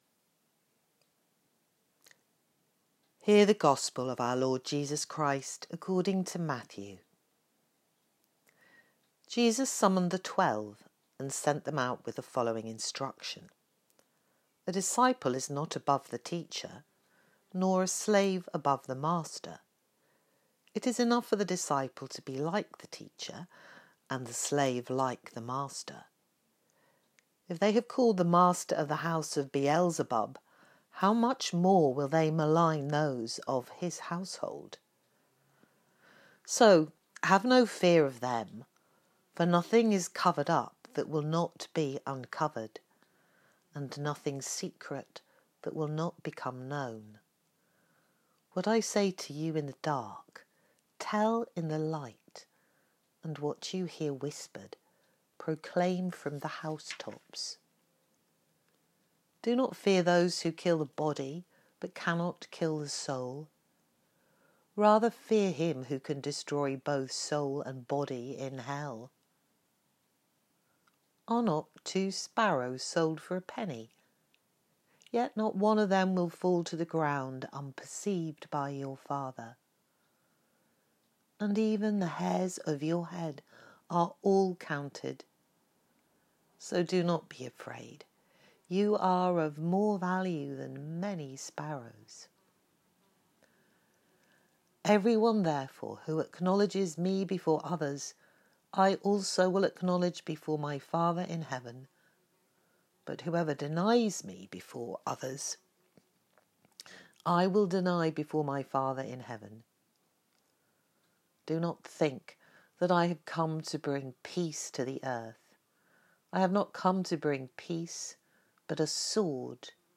Gospel Reading